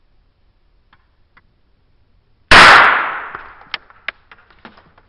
balloon popping
描述：Balloon popping: a quick burst, explosion, bubble bursting, loud pop. Recording with a ZOOMH4n in a sound booth.
标签： popping shot loud explosion explosive boom bang explode pop balloon bubble burst OWI bomb